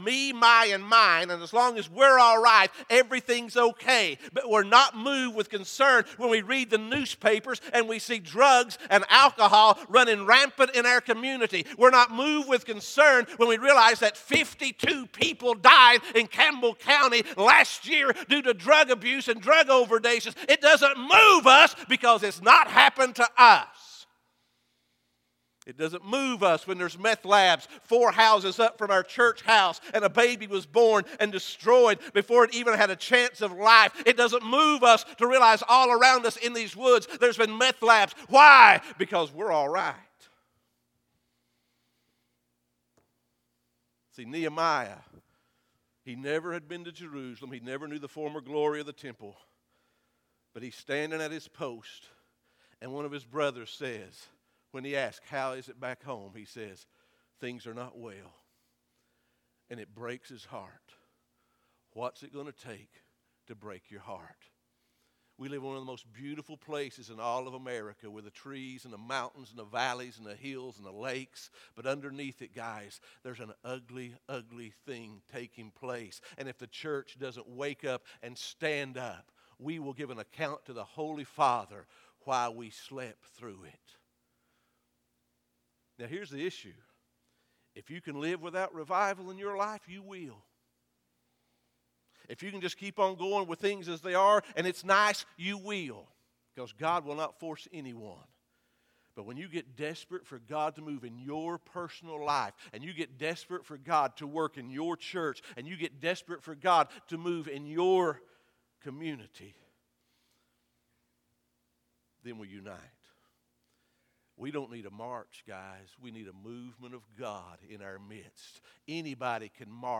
In this sermon, the preacher discusses the importance of maintaining lines of separation and pursuing holiness and righteousness in our churches.